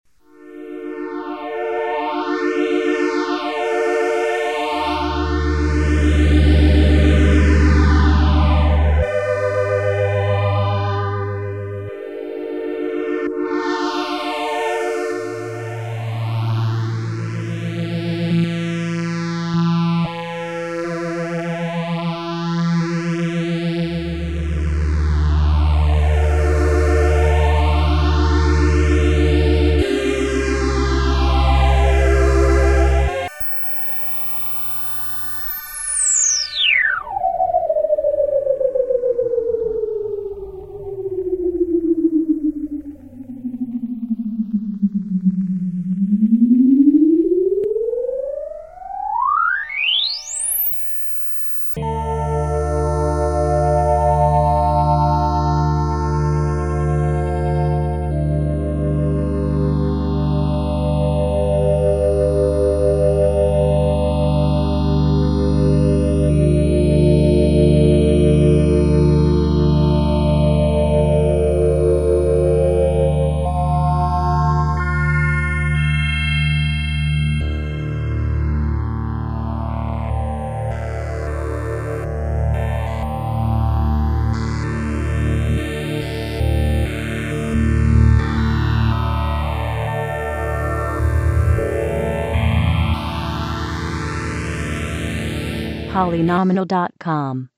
cycle wavetable2 (+phaser)